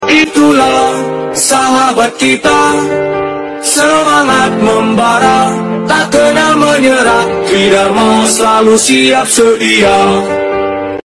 F/A 18D Mighty Hornet SALUTE NO18 sound effects free download